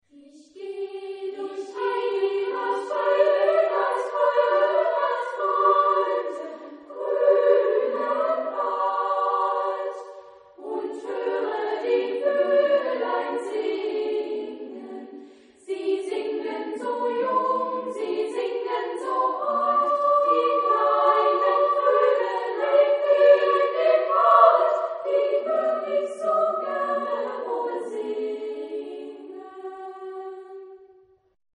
Genre-Stil-Form: Liedsatz ; Volkslied ; weltlich
Chorgattung: SSA  (3 Kinderchor ODER Frauenchor Stimmen )
Tonart(en): G-Dur